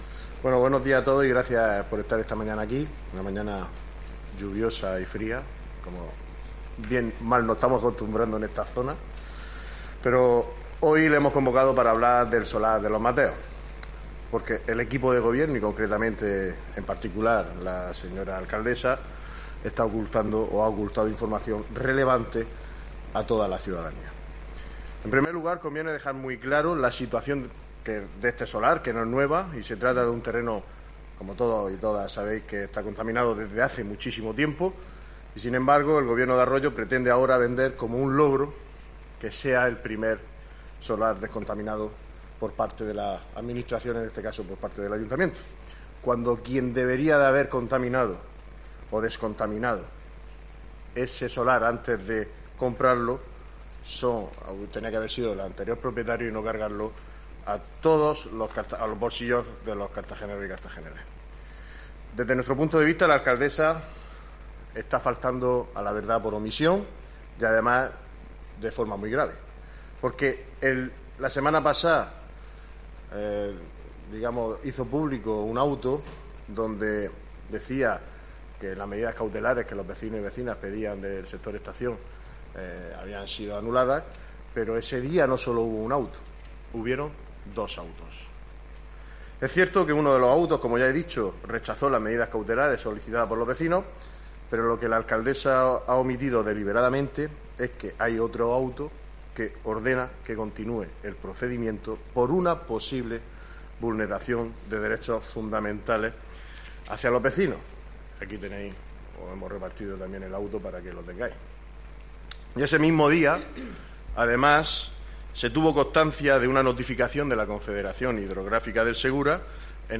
Audio: Rueda de prensa del PSOE.